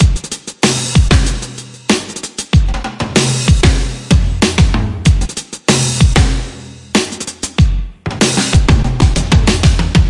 描述：All the dueling pianos loops together with a simple drum track.
标签： 95 bpm Electronic Loops Groove Loops 2.55 MB wav Key : G
声道立体声